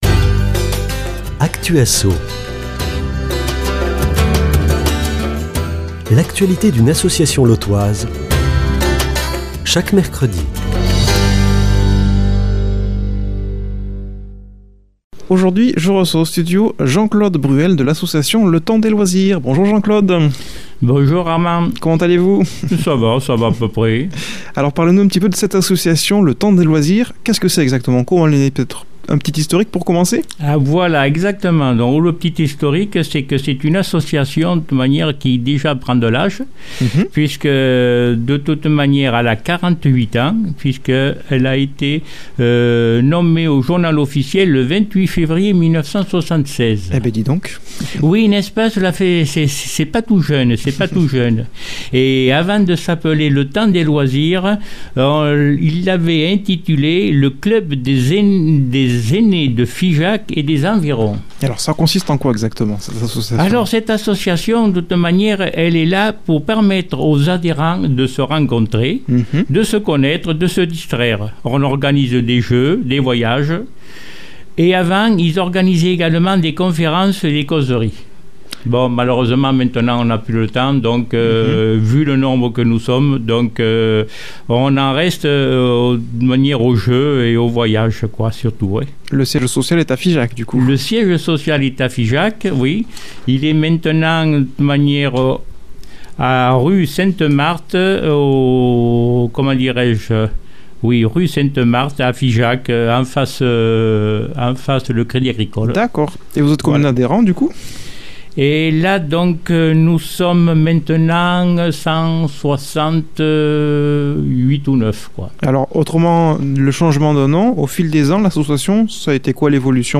invité au studio